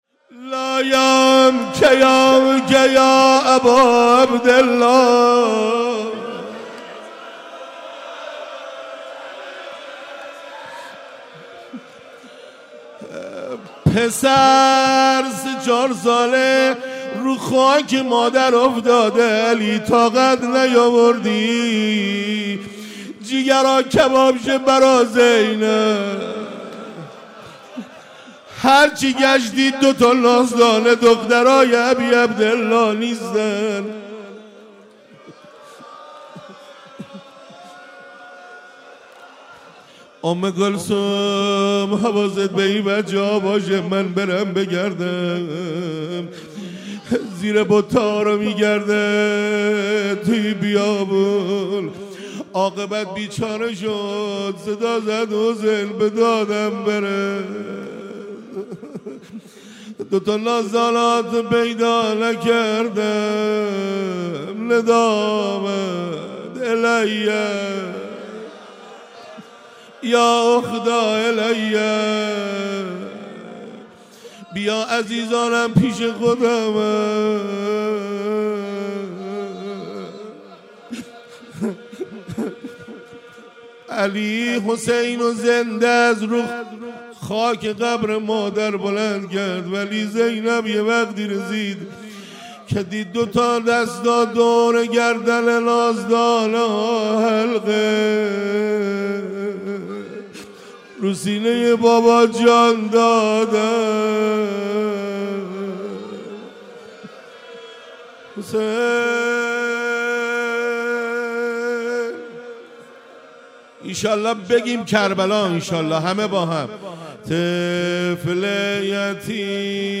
خیمه حضرت فاطمه زهرا سلام الله علیها
فاطمیه 96 - روضه - لا یوم کیومک یا اباعبدالله